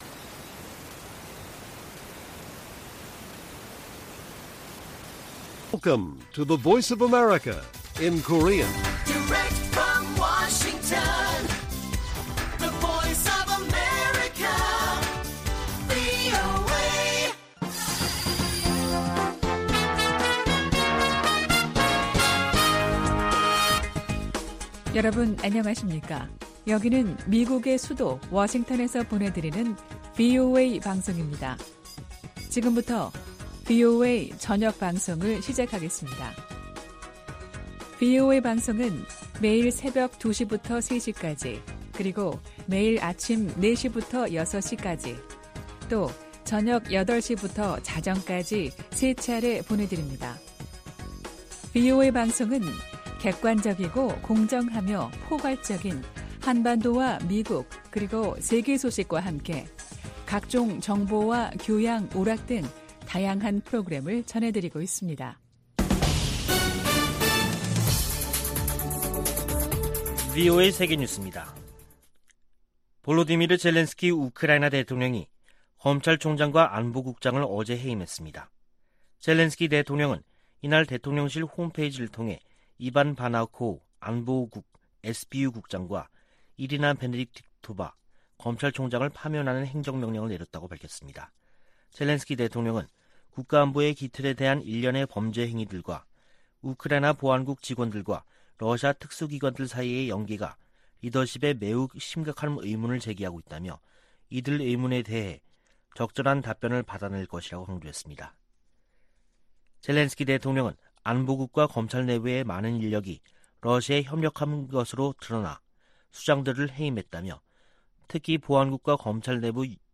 VOA 한국어 간판 뉴스 프로그램 '뉴스 투데이', 2022년 7월 18일 1부 방송입니다. 미 국무부 고위 관리가 정보기술(IT) 분야에 위장 취업한 북한인들과 제3국인들이 제기하는 문제와 위험성을 경고했습니다. 워싱턴 전문가들은 북한의 제재 회피를 방조하는 중국과 러시아를 제재할 필요가 있다고 강조했습니다. 주한미군 규모를 현 수준으로 유지하는 새 회계연도 국방수권법안을 미 하원이 최종 의결했습니다.